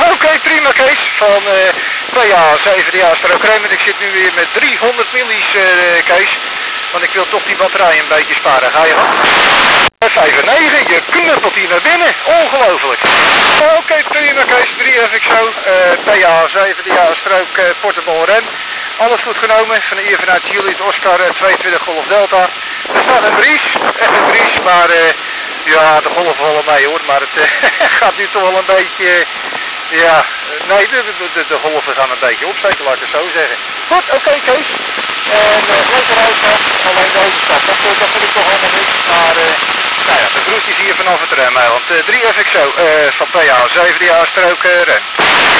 Maximum RF vermogen was 300mW, maar zelfs met 100 mW was het signaal nog goed waarneembaar.